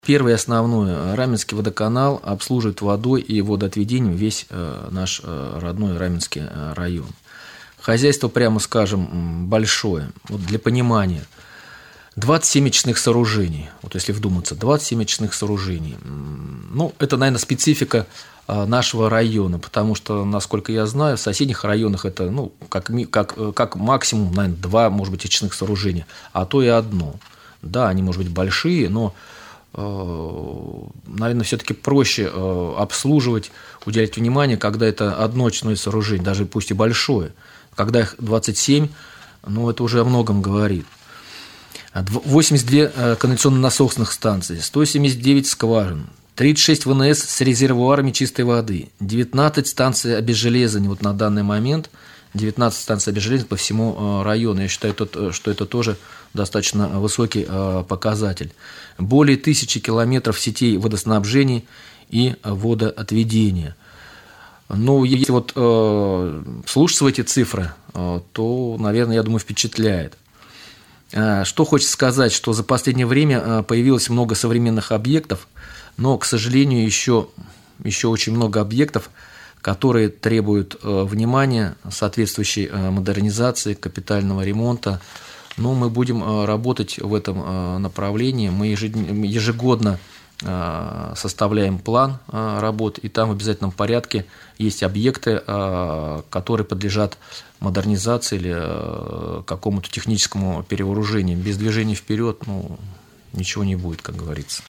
В ходе прямого эфира на Раменском радио